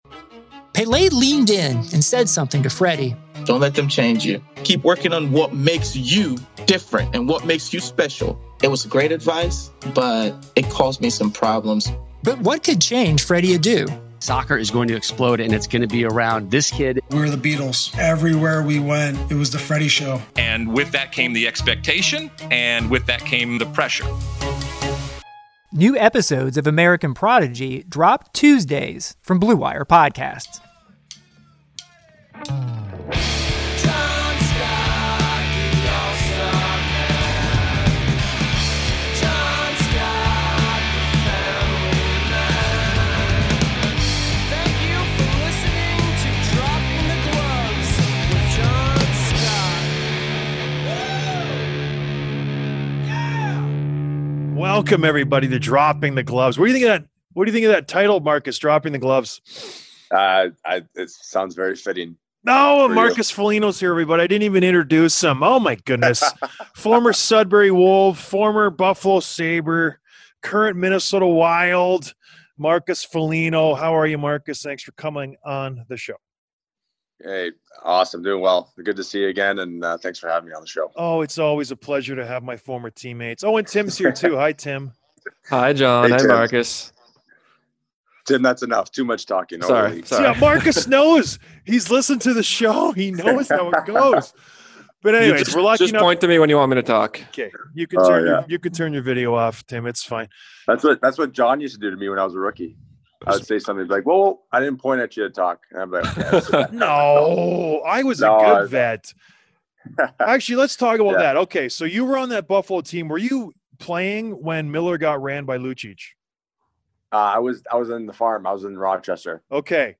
Interview with Marcus Foligno, Minnesota Wild